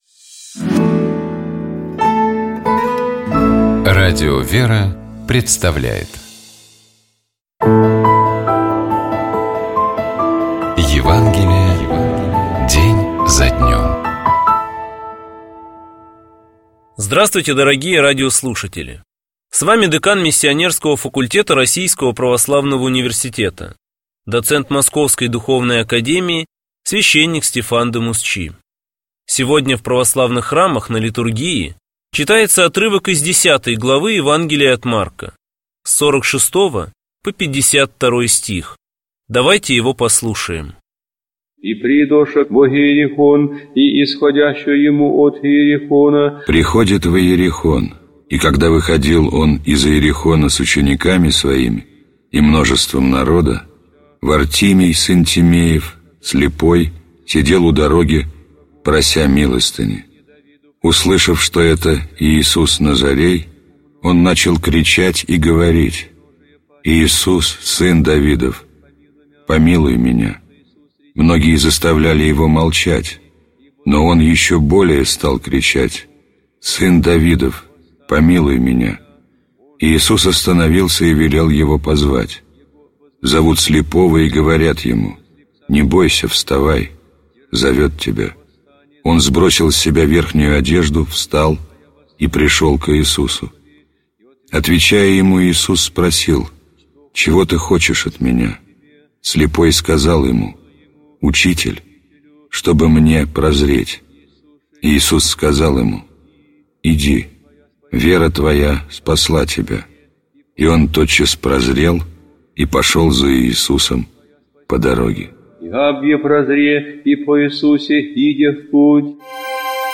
Читает и комментирует священник